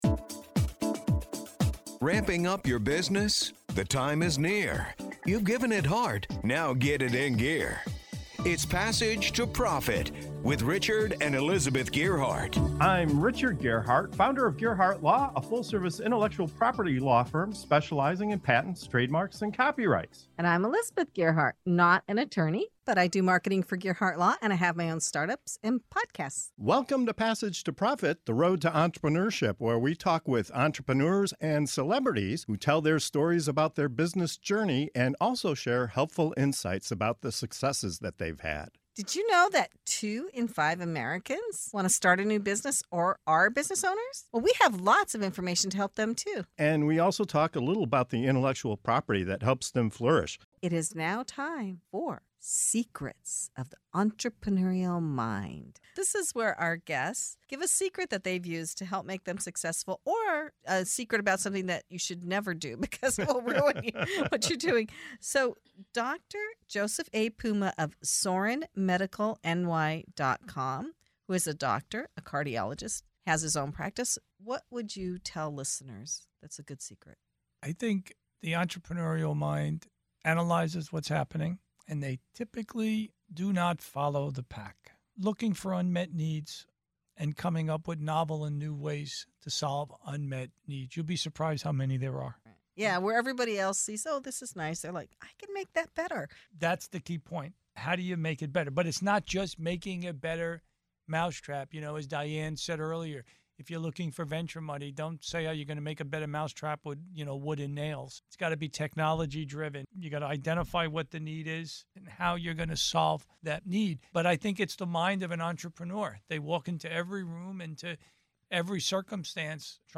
Don't miss these candid conversations packed with wisdom and practical advice!